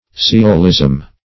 Search Result for " sciolism" : Wordnet 3.0 NOUN (1) 1. pretentious superficiality of knowledge ; The Collaborative International Dictionary of English v.0.48: Sciolism \Sci"o*lism\, n. [See Sciolist .]